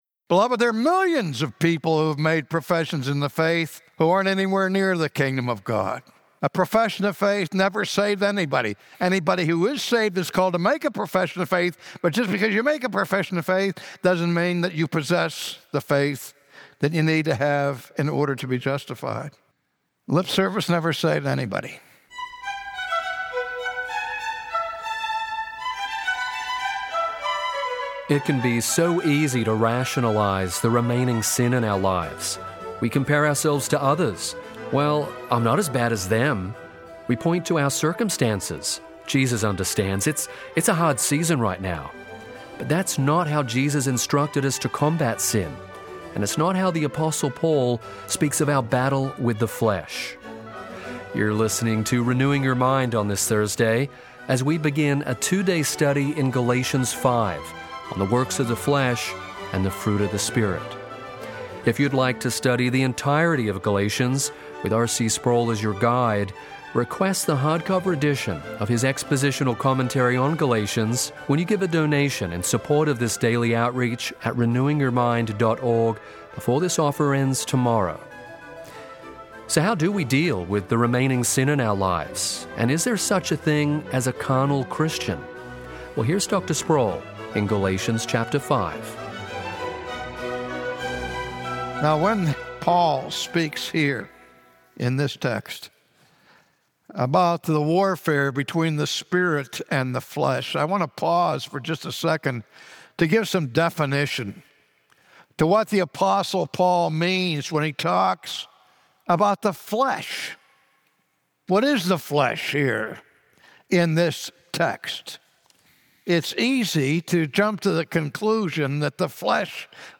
Christians are no longer under the dominion of sin. As we grow in sanctification, we must put to death the old desires of the flesh. From his sermon series in the book of Galatians, today R.C. Sproul exhorts us to examine our lives and pursue holiness in the power of the Holy Spirit.